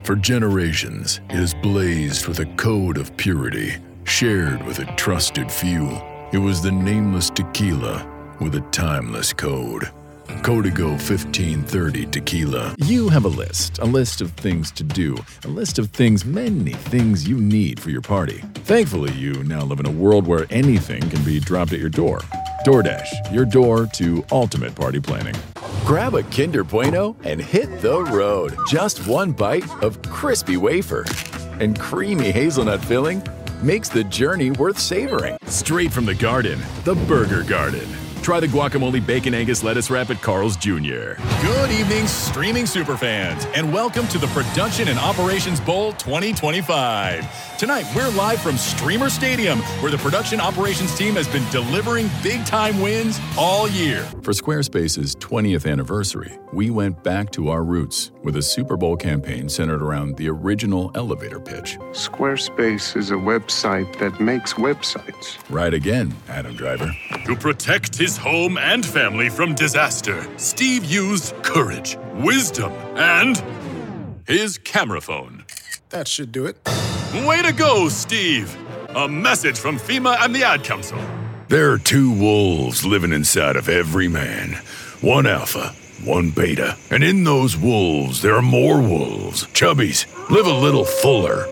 Voiceover : Commercial : Men